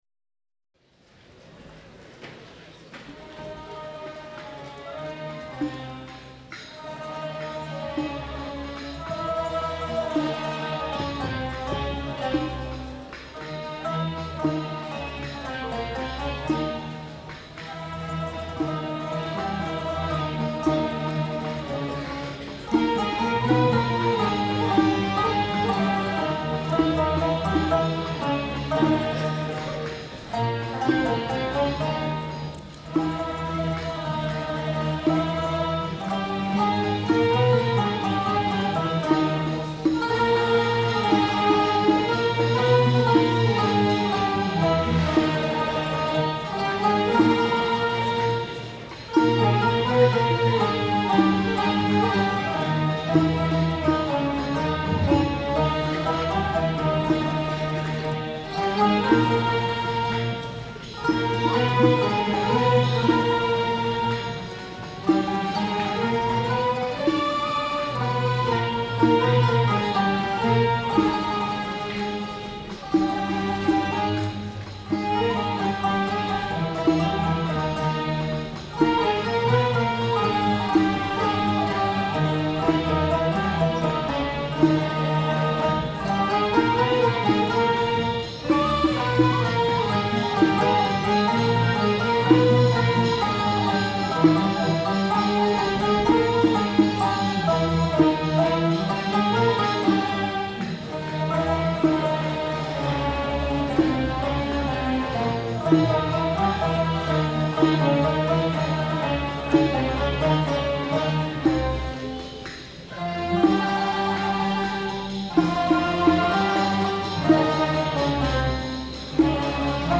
Arabic Classic Variety 1